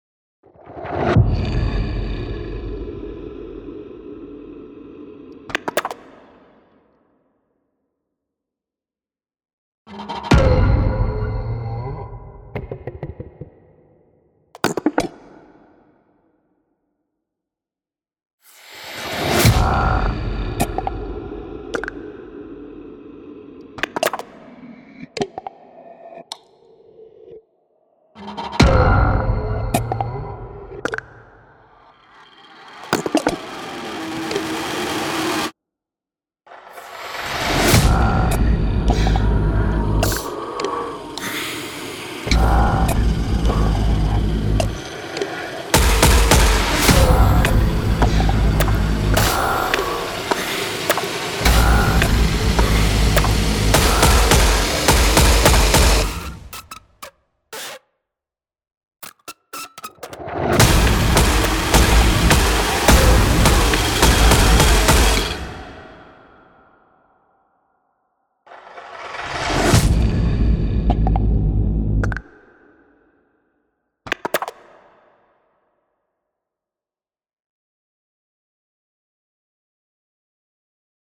Vocal horror sounds for tense trailers
• Terrifying vocal FX designed for horror compositions
• Engines to layer and build risers, whooshes, hits and rhythmic sequences
Unleash the horror of the human voice
Create custom hits, spine-chilling risers, and one-of-a-kind rhythmic patterns that stand out in every composition.
Product type: Kontakt instruments